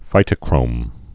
(fītə-krōm)